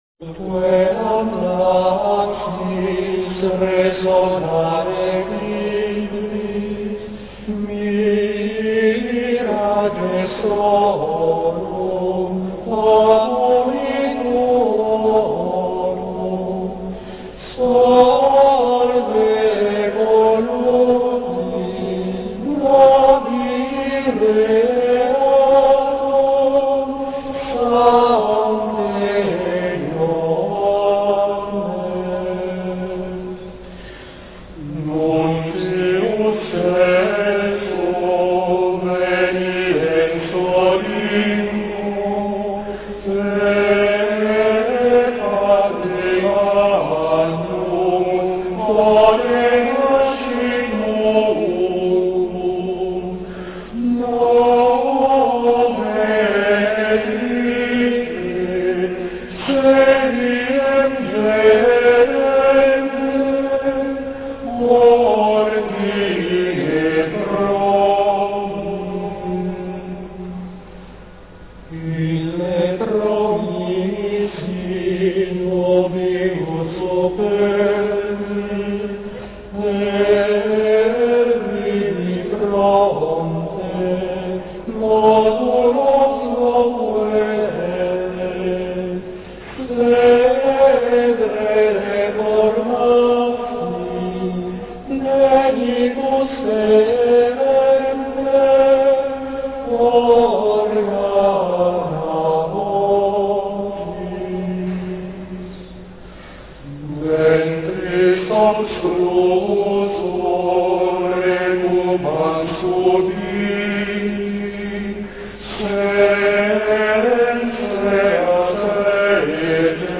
Canto Gregoriano
053.ut.queant.laxis_caanto.gregoriano.wav